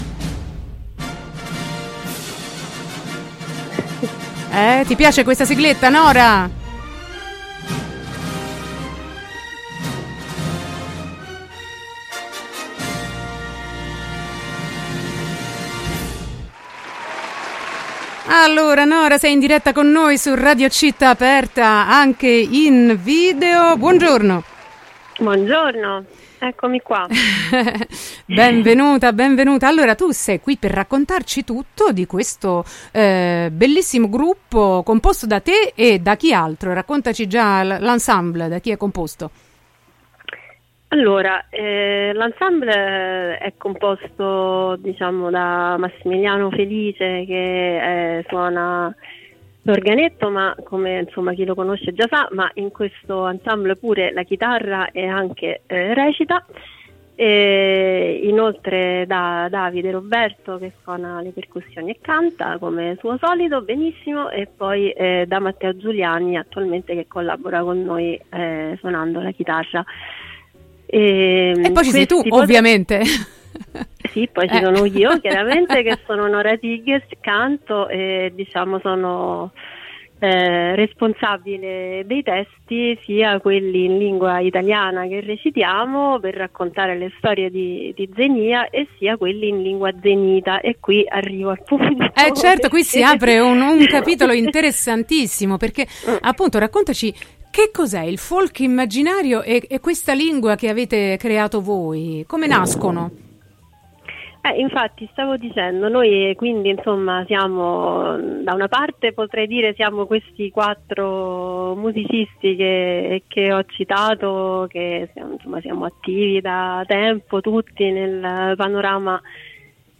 Il folk immaginario di Zenìa, intervista